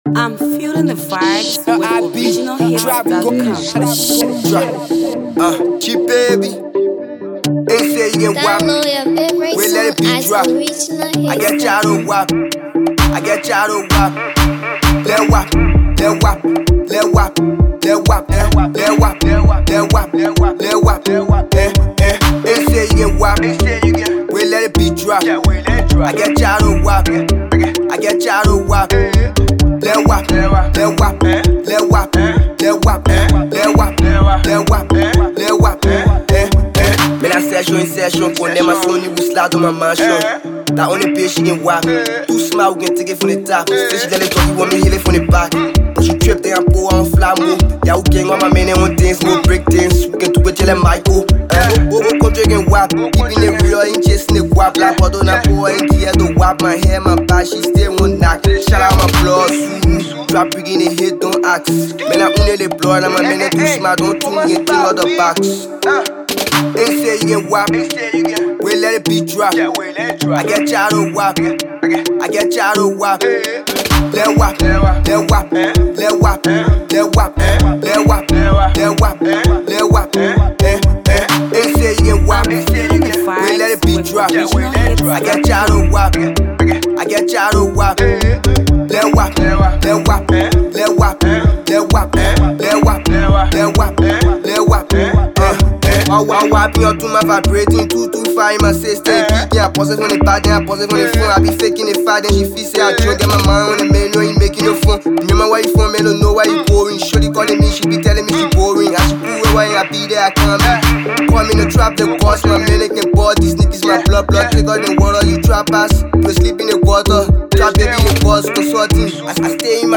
Liberian uprising trap artist